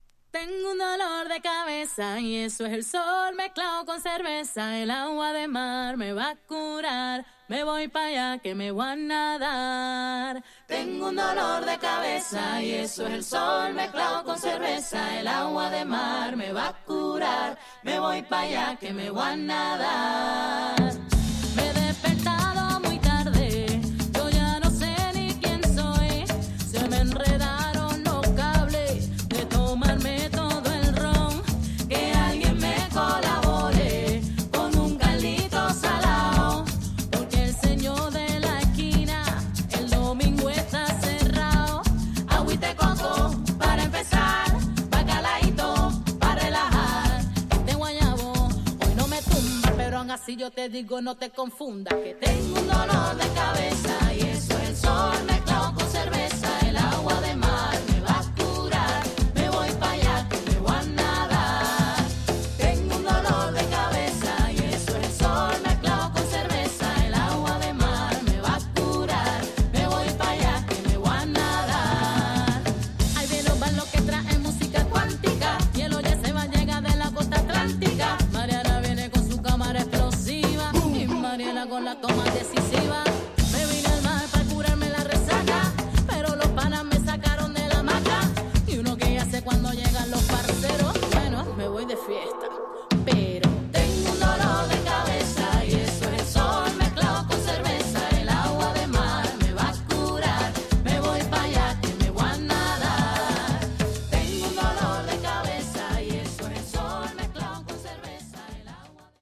Tags: Tropical , Spain